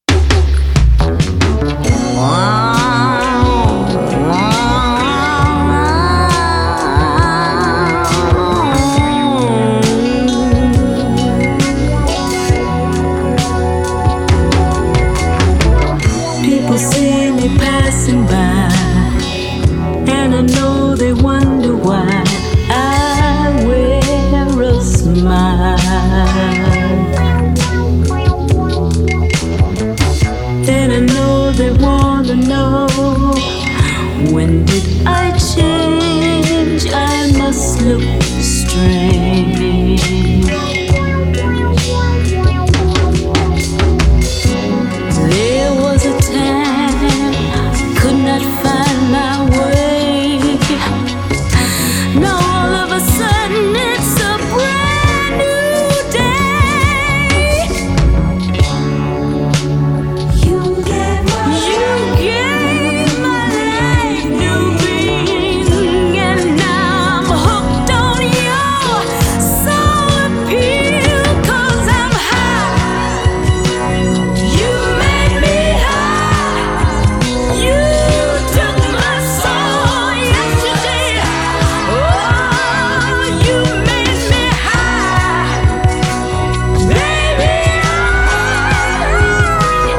B1: Original Unreleased Demo Mix